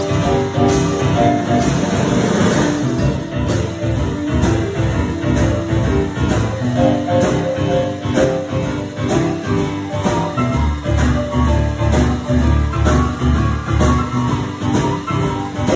Retour en sons sur …Jazz en mars au collège !
Au piano
batterie